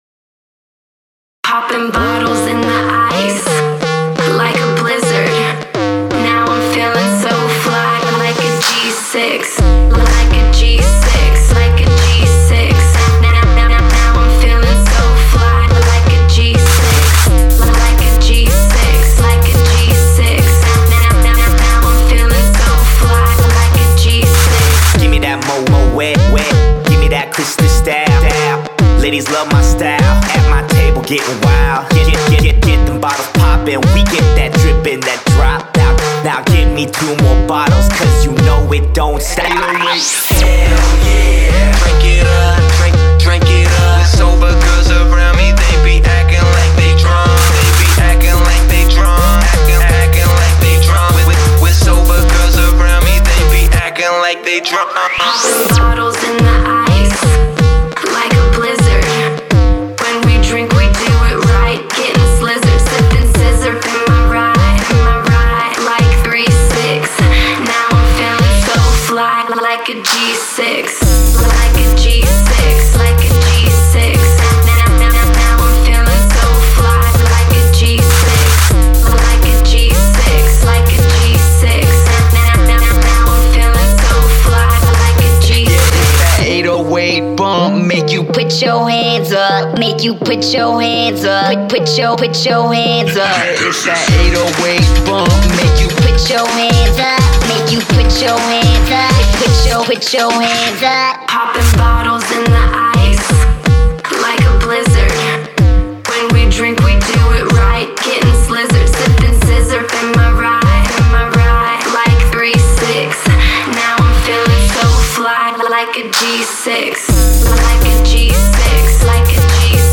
BPM62-125
Audio QualityPerfect (High Quality)
Edit 2020-07-21: Added genre string “Hip House”.